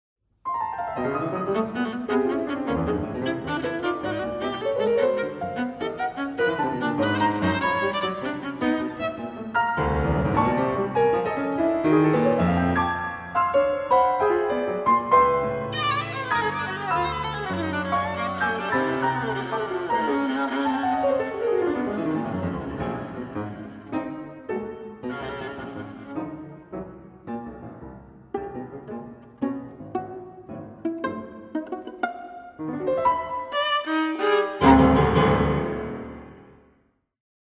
Sonata for viola and piano op. 117